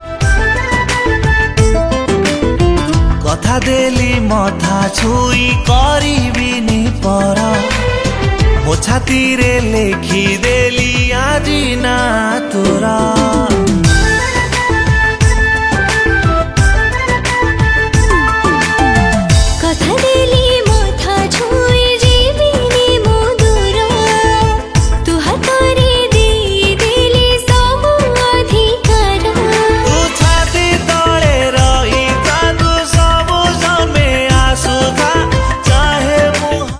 Odia Album Ringtones
Romantic song